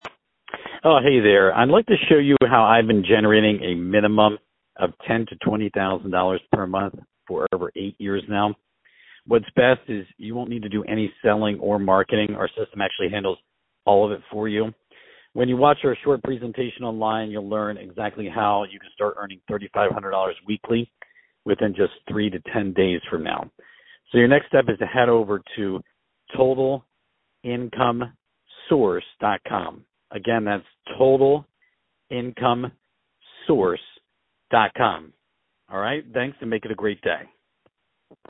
Scams Robo Calls